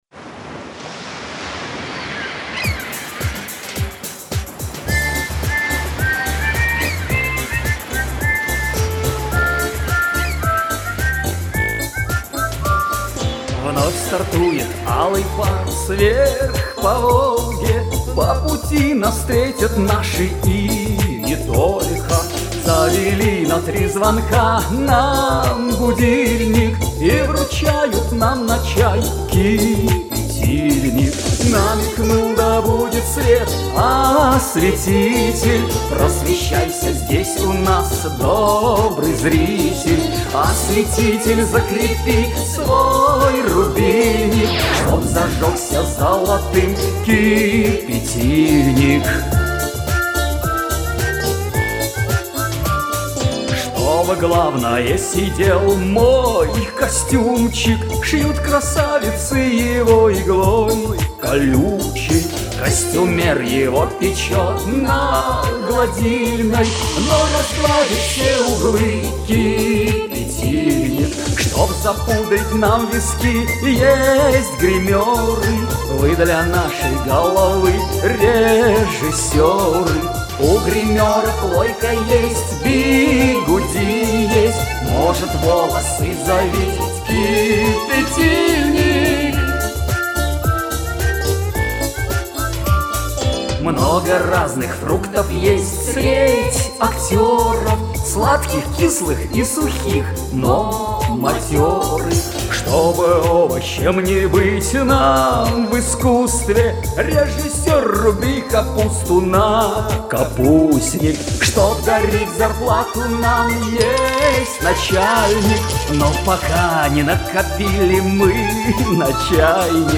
Демо озвучивания